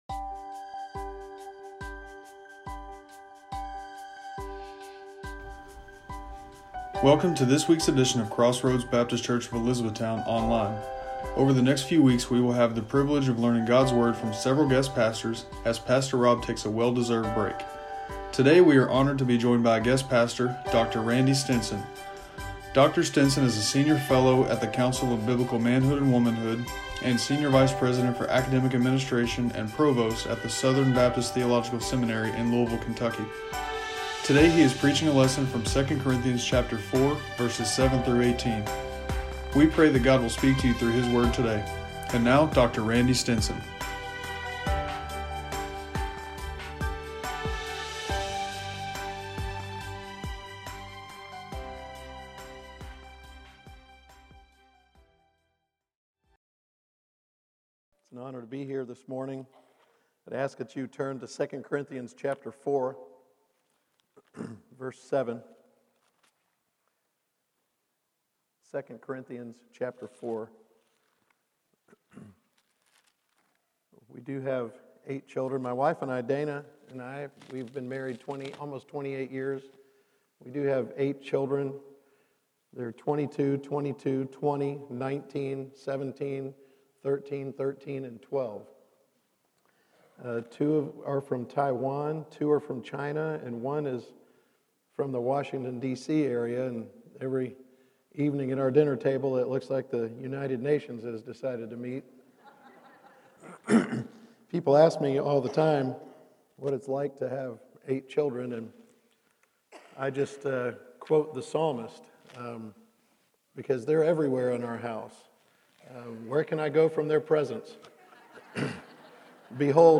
Individual Sermons